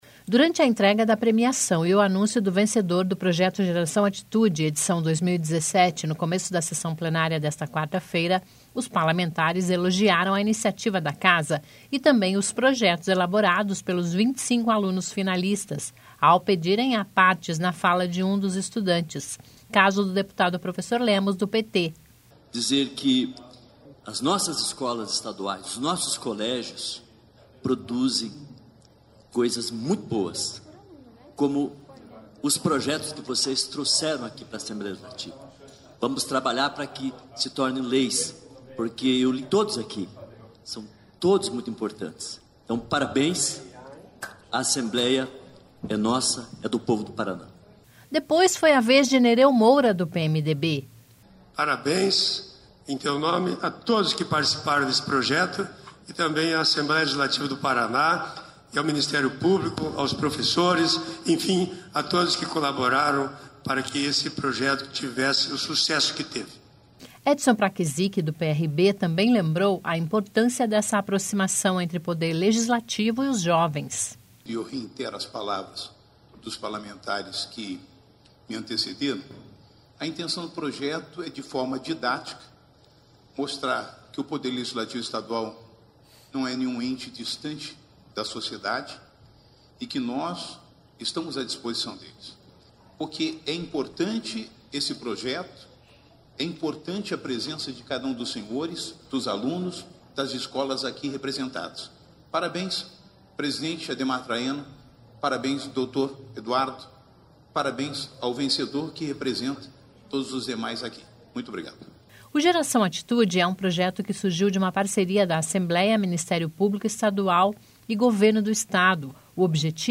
Durante a entrega da premiação e o anúncio do vencedor do projeto Geração Atitude/2017, no começo da sessão desta quarta-feira (22), os parlamentares elogiaram a iniciativa da Casa e também os projetos elaborados pelos 25 alunos finalistas,  ao pedirem "apartes" na fala de um dos estudantes.
Depois foi a vez de Nereu Moura (PMDB).
Edson Praczyki (PRB) também lembrou a importância dessa aproximação entre Poder Legislativo e os jovens.